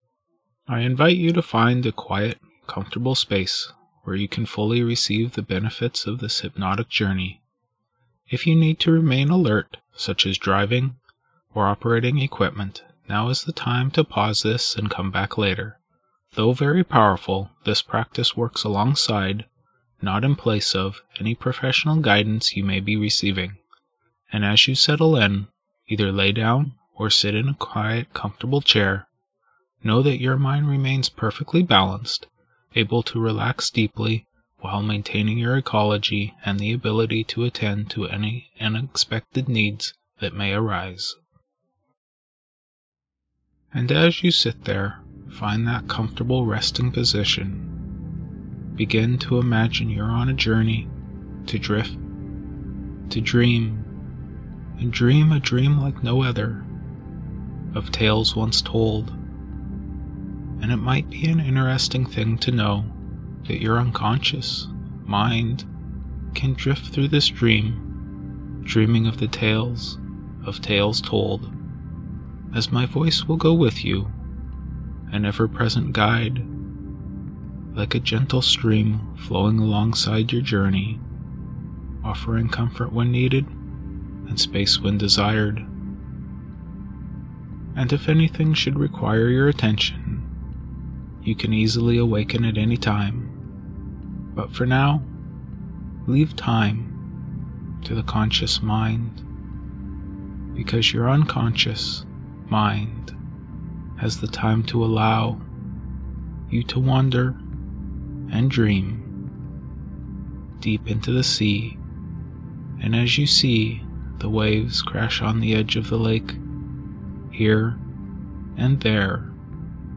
The Worrier is a powerful hypnosis session designed to help you quiet the noise of an overactive mind, release tension, and ease into a deep, restorative sleep.
✔ A deeply calming induction to slow down racing thoughts
✔ Soothing background music designed to promote deep sleep